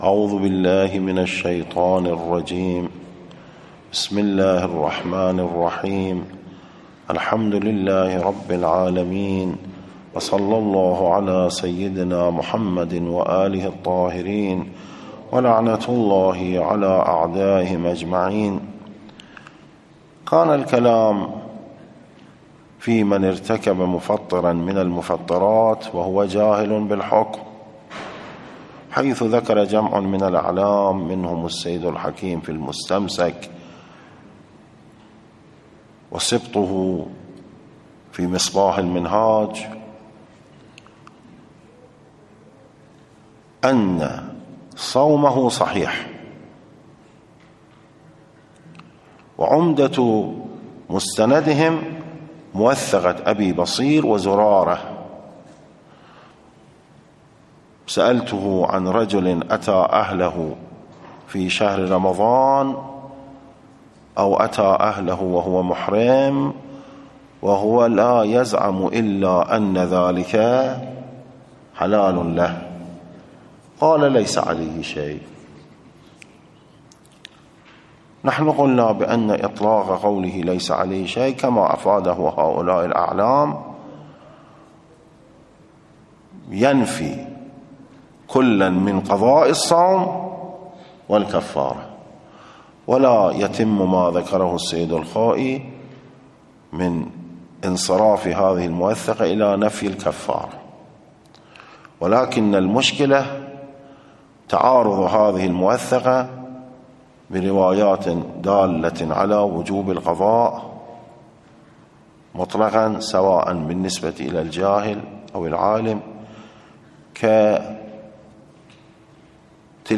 نص الدّرس 41 ، الاثنین 27 جمادی الاول 1445